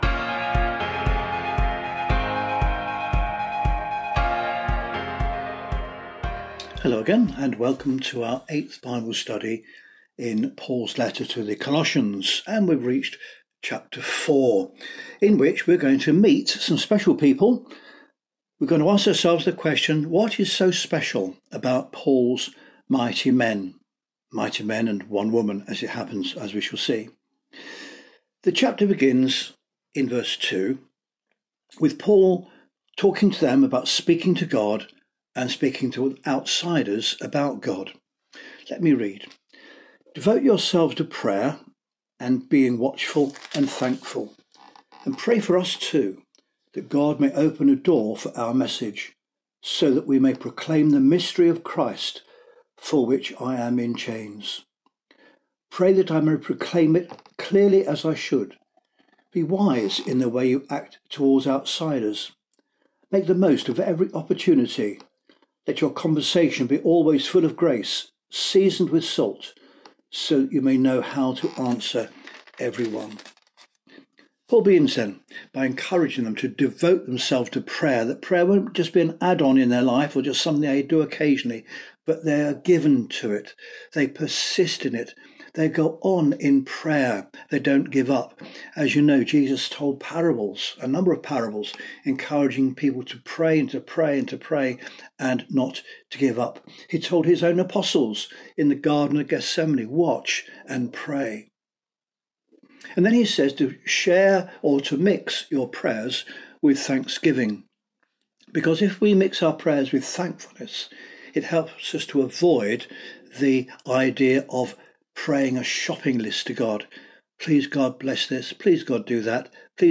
Listen to Sunday sermons from Derby City Church.